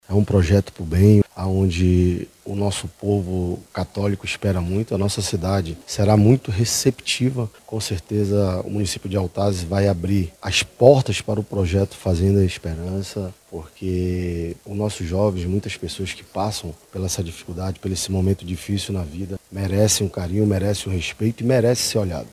SONORA-2-THOME-NETO-.mp3